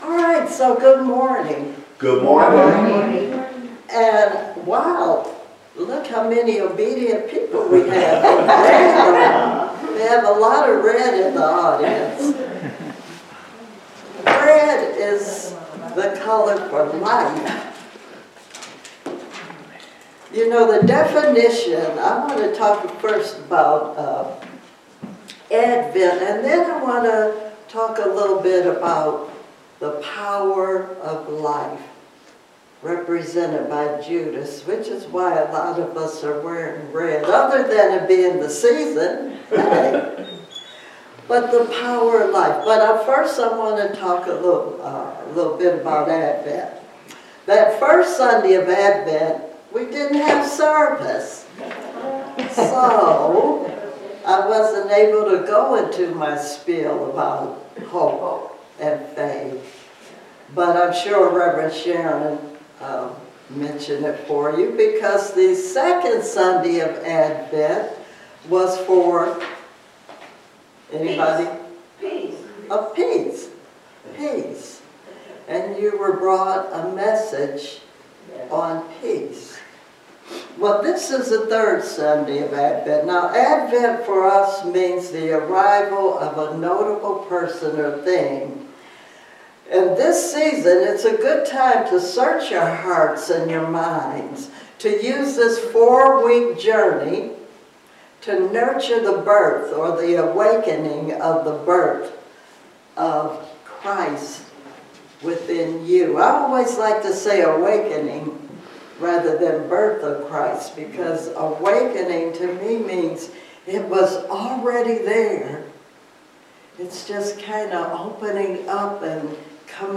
Sermons 2026